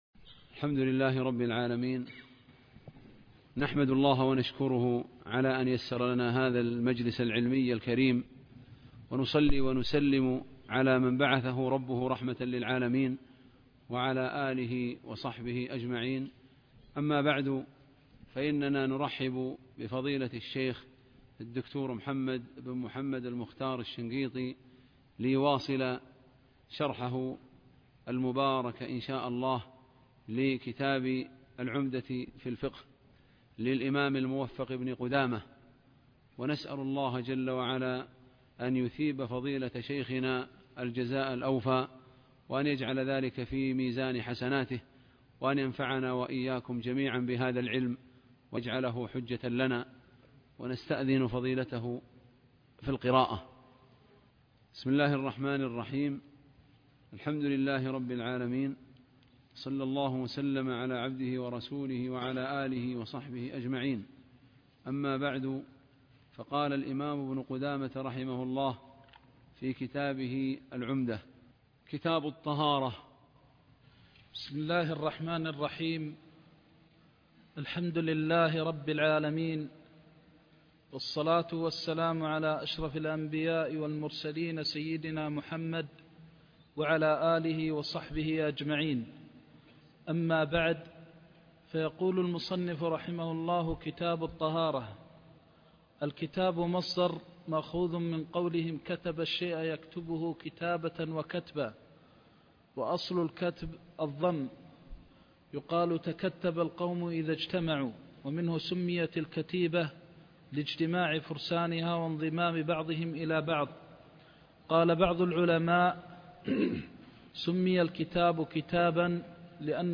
عمدة الفقه - درس الرياض (3)